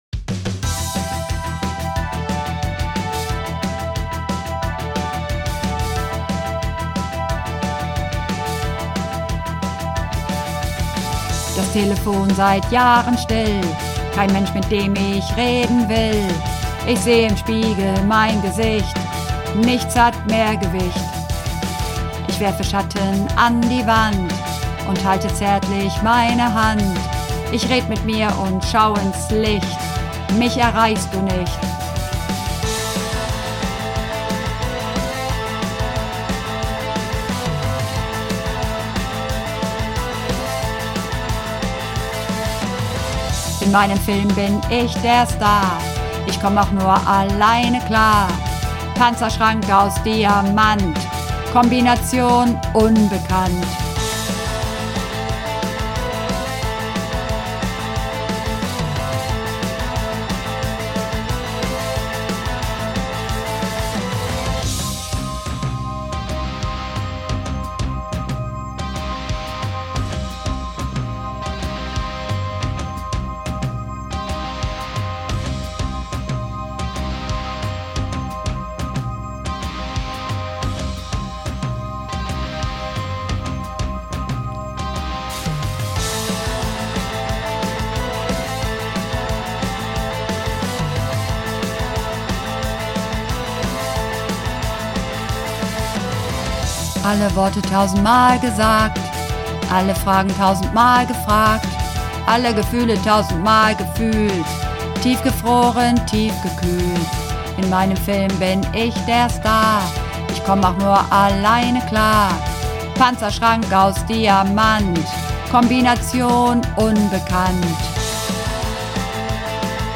Übungsaufnahmen - Eiszeit
Eiszeit (Bass - Frauen)
Eiszeit__2_Bass_Frauen.mp3